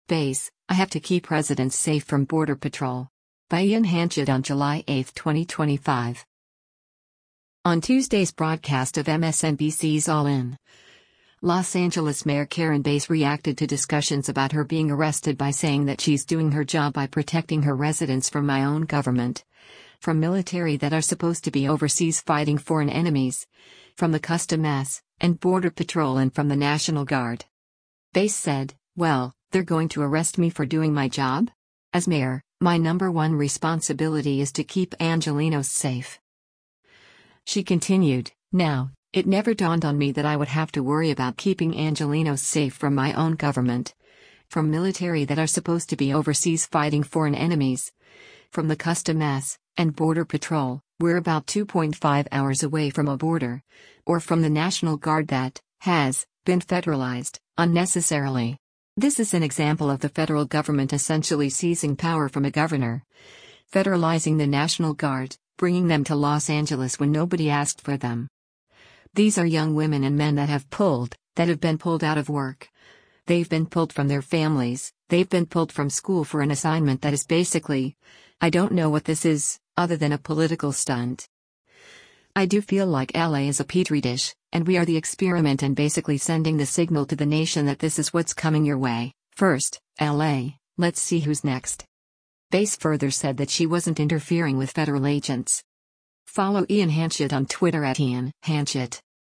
Video Source: MSNBC
On Tuesday’s broadcast of MSNBC’s “All In,” Los Angeles Mayor Karen Bass reacted to discussions about her being arrested by saying that she’s doing her job by protecting her residents “from my own government, from military that are supposed to be overseas fighting foreign enemies, from the Custom[s] and Border Patrol” and from the National Guard.